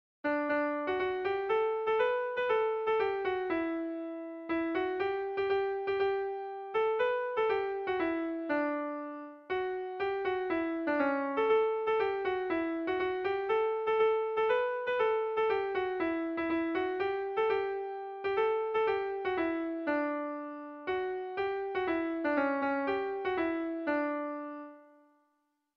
Air de bertsos - Voir fiche   Pour savoir plus sur cette section
Tragikoa
ABDEBD2